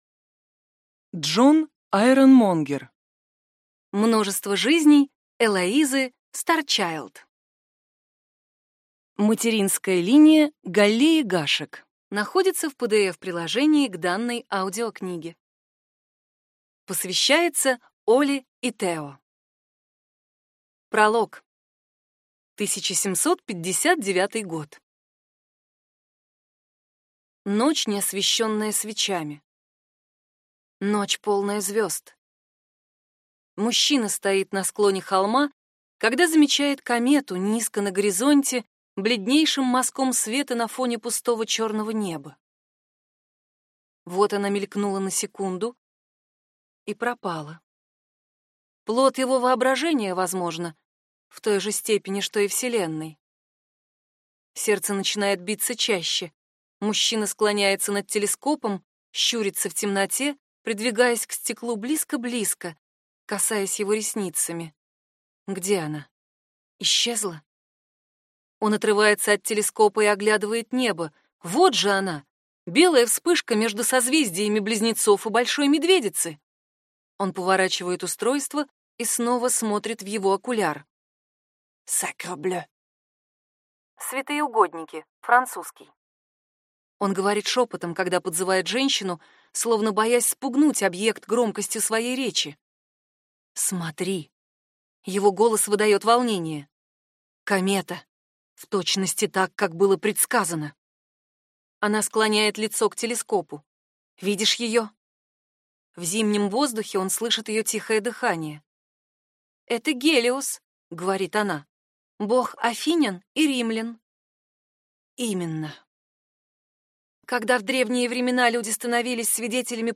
Аудиокнига Множество жизней Элоизы Старчайлд | Библиотека аудиокниг